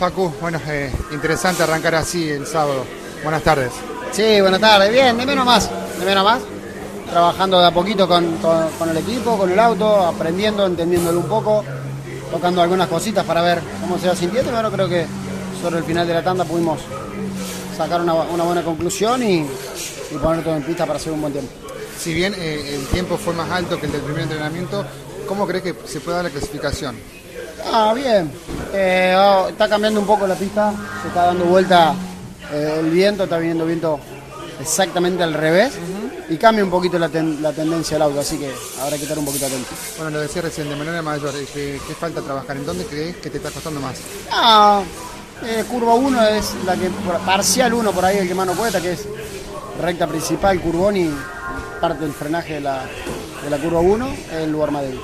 El testimonio